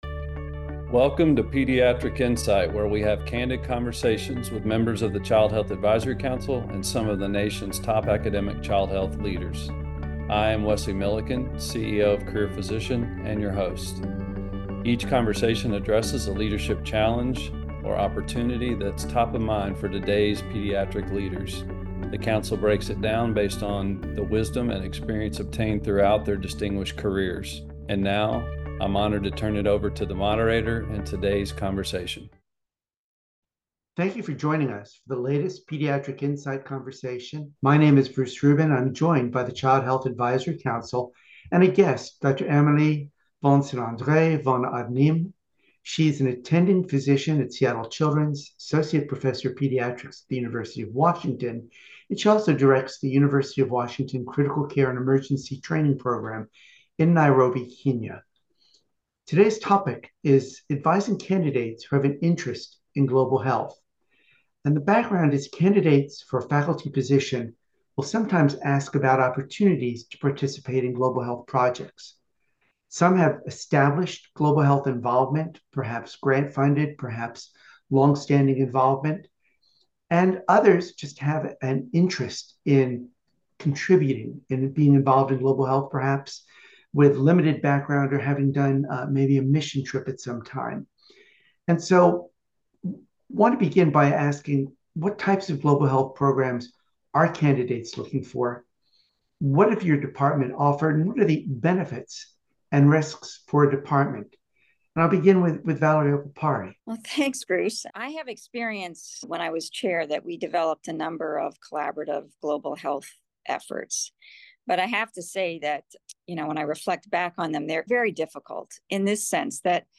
Inspired by Malcolm Gladwell’s concept and shaped by decades of leadership experience, the panel explores how master connectors do far more than match mentors and mentees.